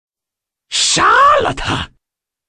变态男声杀了他音效免费音频素材下载